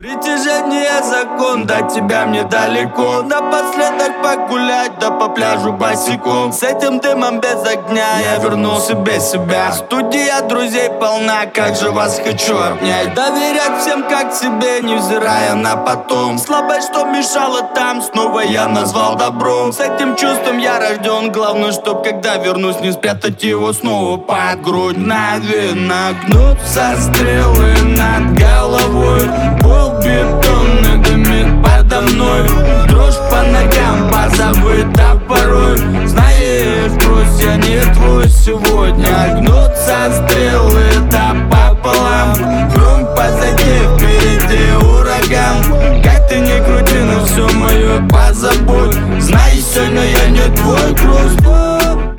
• Качество: 128, Stereo
мужской голос
лирика
спокойные